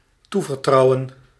Ääntäminen
Synonyymit vertrouwen vertrouwen hebben in Ääntäminen Tuntematon aksentti: IPA: /ˈtuvərˌtrʌwə(n)/ Haettu sana löytyi näillä lähdekielillä: hollanti Käännös Ääninäyte Verbit 1. confier France Luokat Verbit Yhdyssanat